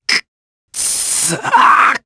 Ezekiel-Vox_Dead_jp.wav